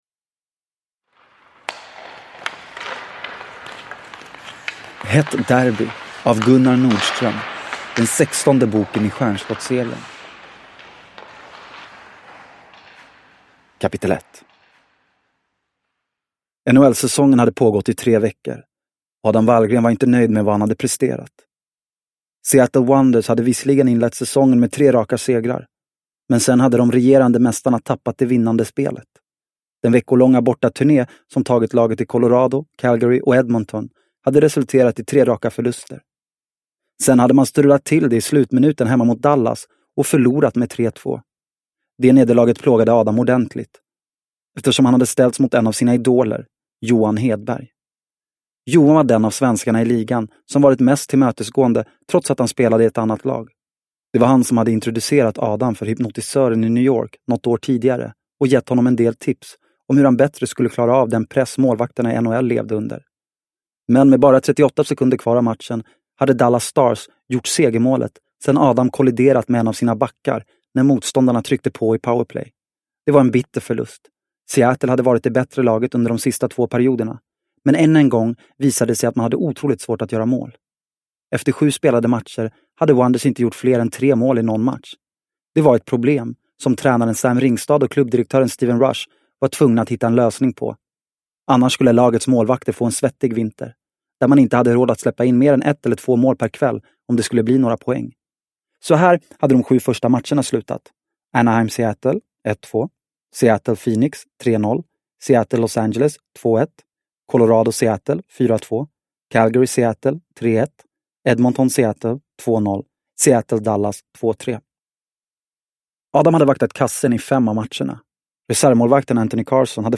Hett derby – Ljudbok – Laddas ner